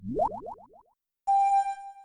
Yawn.mp3